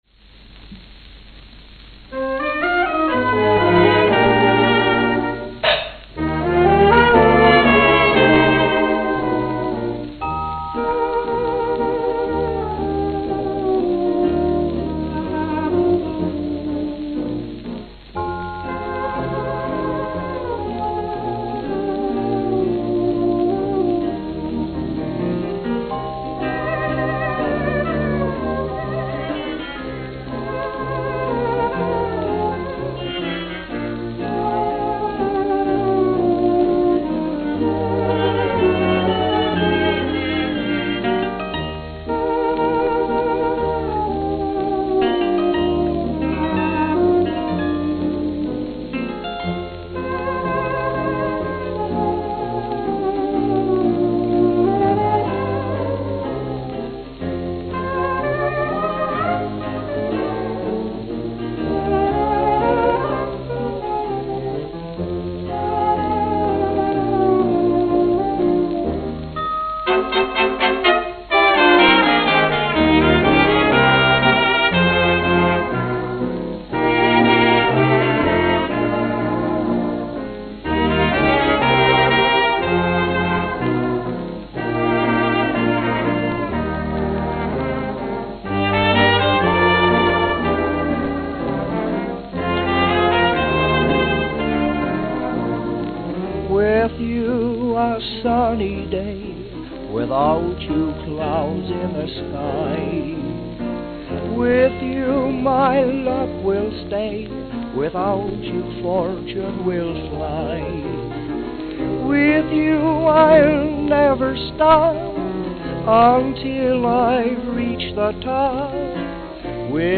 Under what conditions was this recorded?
New York, New York New York, New York Note: Studio sounds before and after.